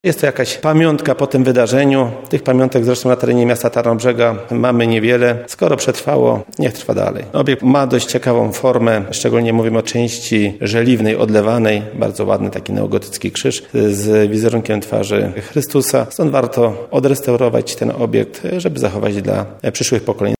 Mówi przewodniczący osiedla Miechocin, Waldemar Stępak.